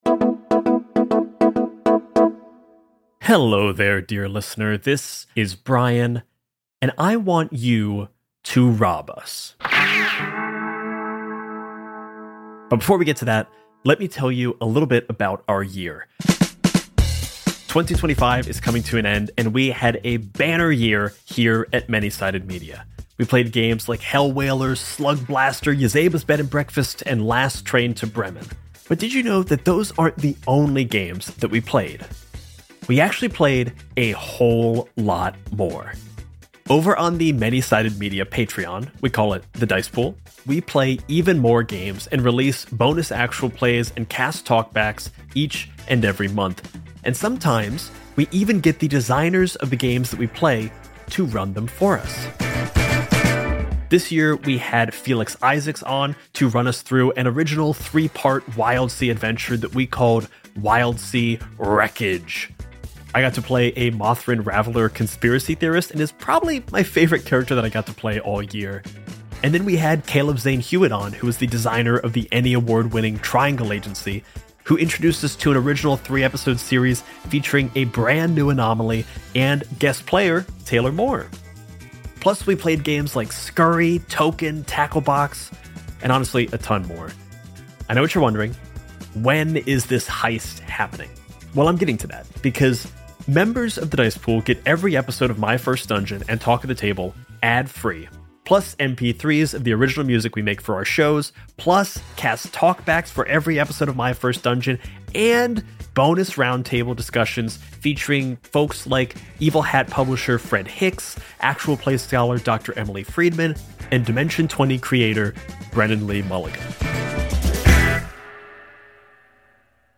Talk of the Table is a TTRPG talk show